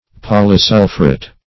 Search Result for " polysulphuret" : The Collaborative International Dictionary of English v.0.48: Polysulphuret \Pol`y*sul"phu*ret\, n. (Chem.) A polysulphide.